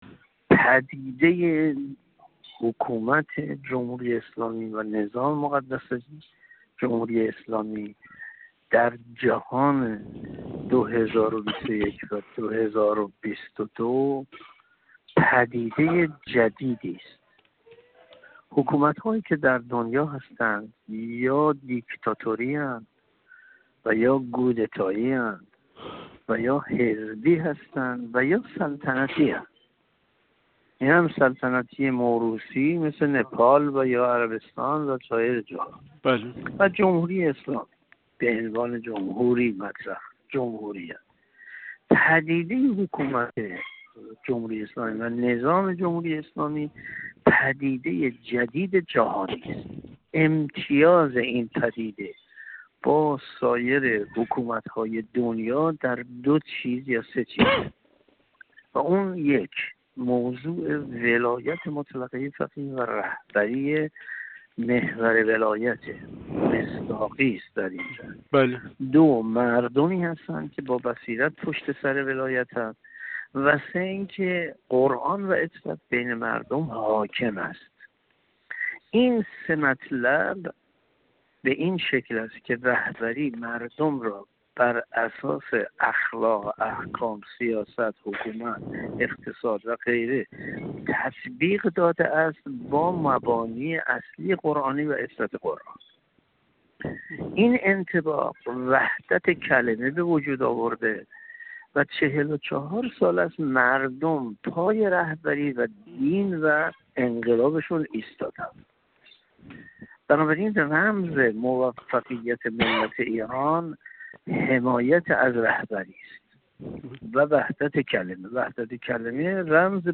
گفت‌وگو با ایکنا